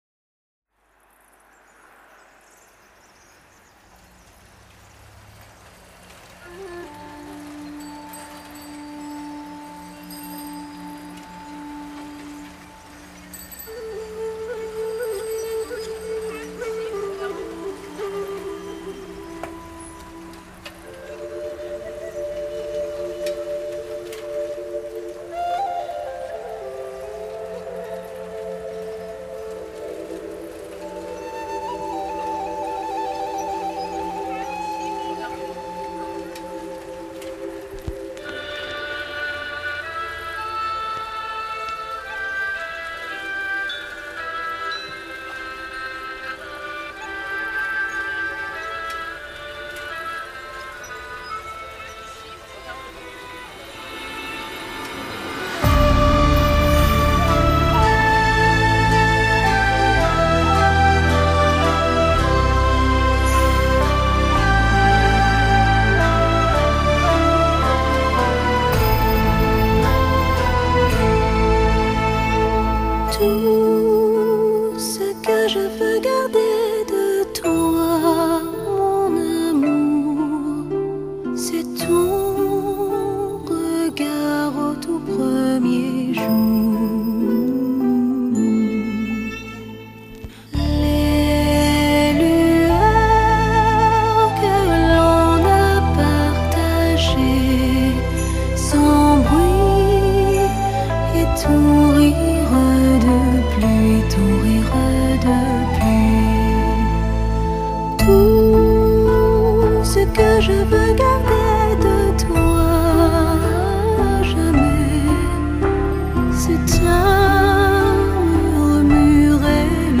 有人叫她法国温婉天后，有人叫她疗伤天后，还有的叫她香颂天后，她的歌